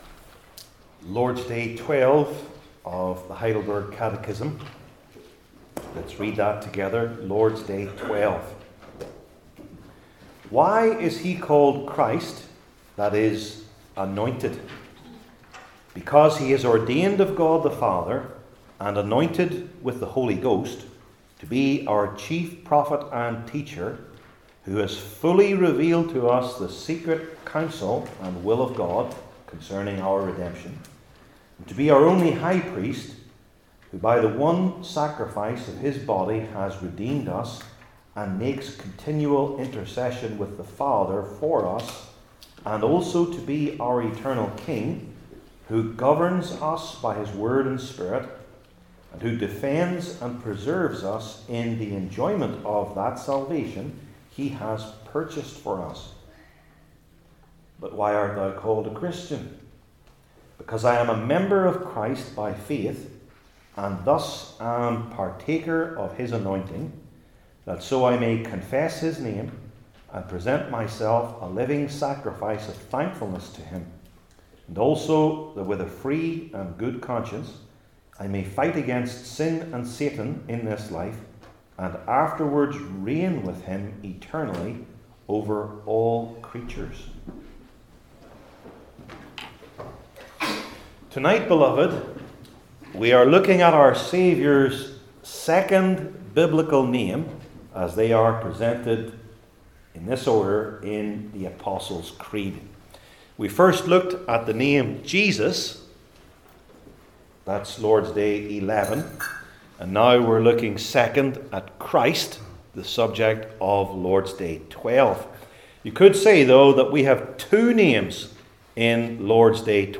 Heidelberg Catechism Sermons I. Predicted II.